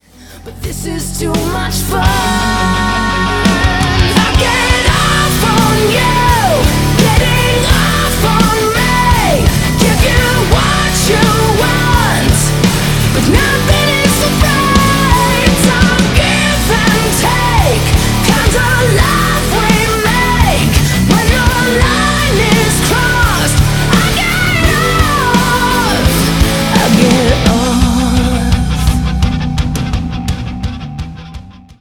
• Качество: 320, Stereo
громкие
женский вокал
зажигательные
Hard rock